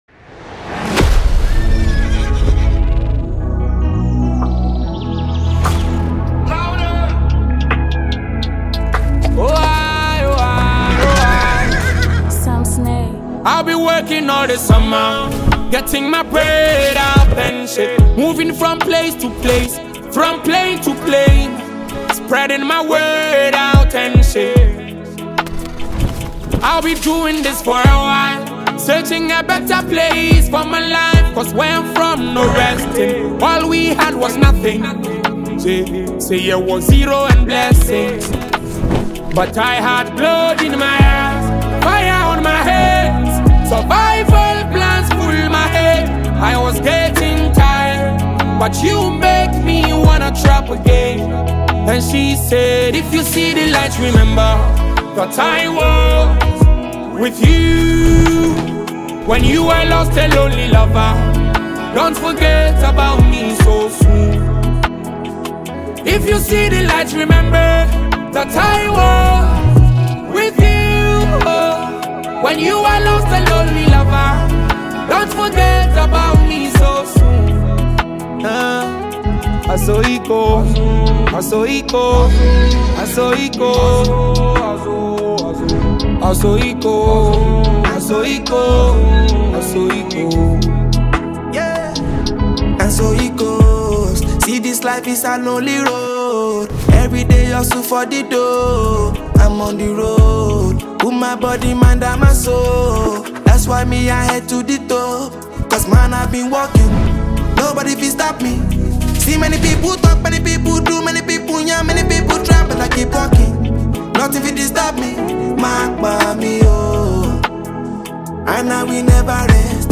R&B
soulful collaboration
raw, emotive verses in his signature storytelling style
velvety vocals